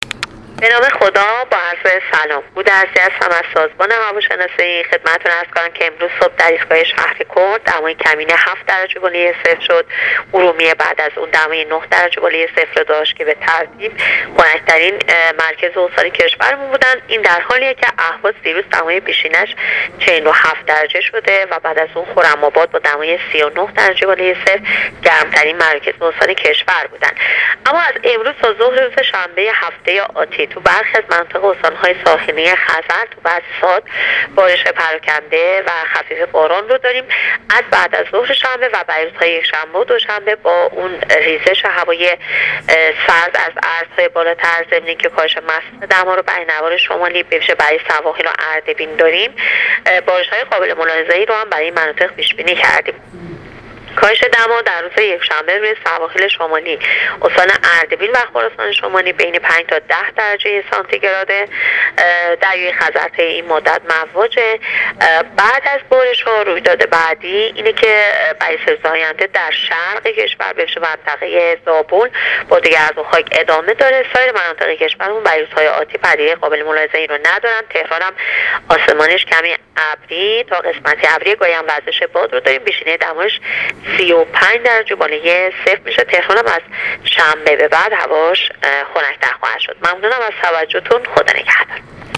گزارش رادیو اینترنتی وزارت راه و شهرسازی از آخرین وضعیت آب‌ و هوای ۲۷ شهریور ۹۸/ بارش باران در نوار شمالی کشور از روز شنبه / خنک شدن هوای پایتخت از ابتدای هفته آینده